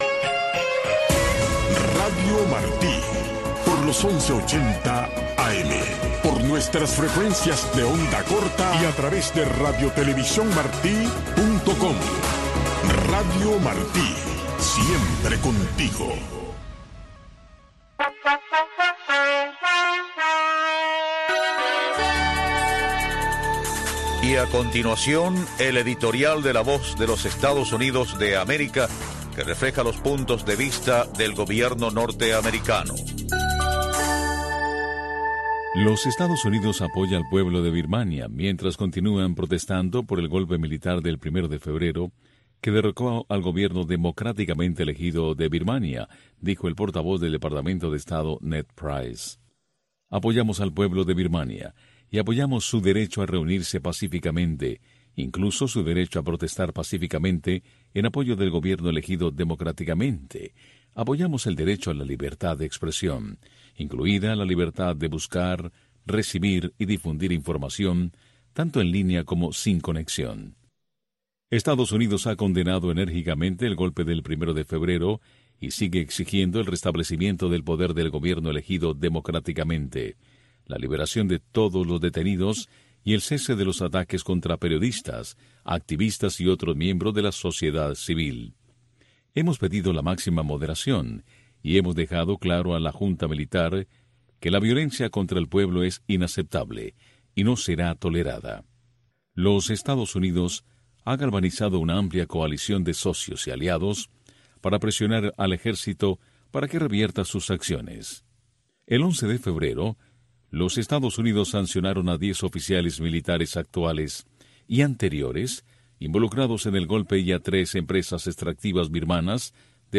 La Santa Misa
PROGRAMACIÓN EN-VIVO DESDE LA ERMITA DE LA CARIDAD